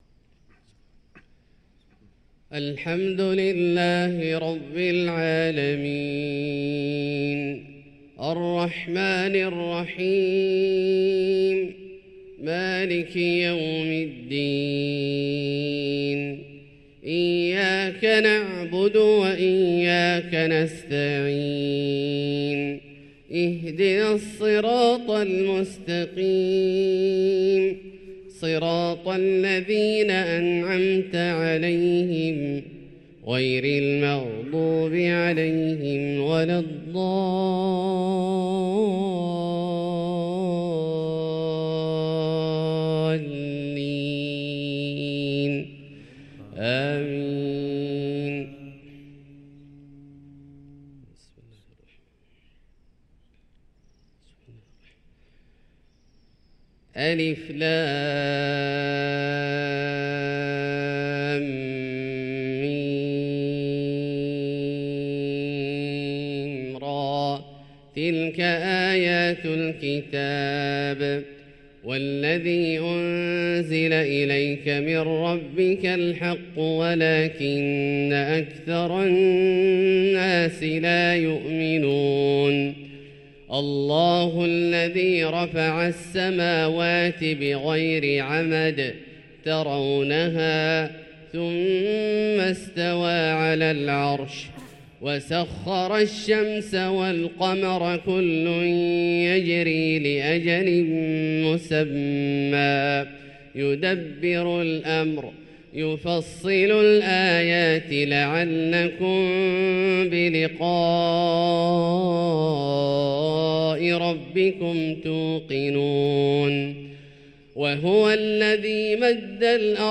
صلاة الفجر للقارئ عبدالله الجهني 12 ربيع الأول 1445 هـ